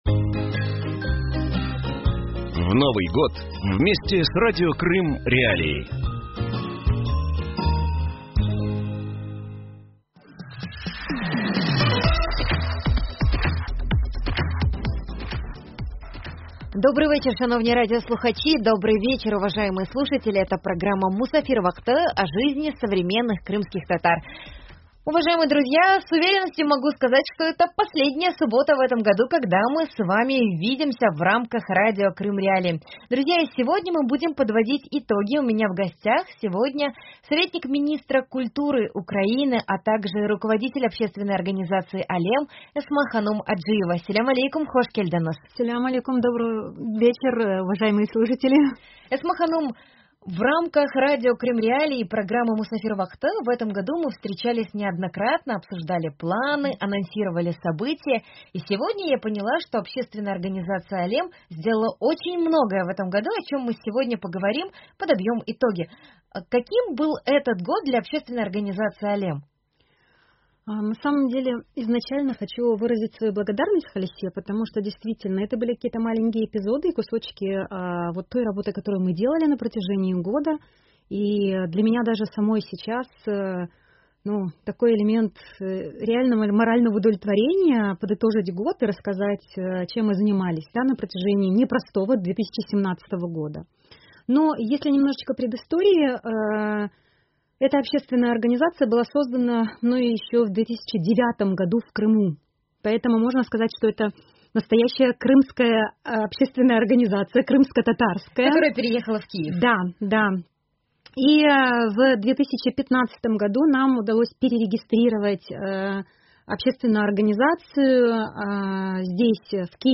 В последнюю субботу уходящего года, мы подводим итоги и вспоминаем мероприятия, которые организовывали представители общественной организации «Alem». Наша гостья рассказывает о культурном самовыражении в современном обществе.